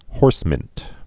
(hôrsmĭnt)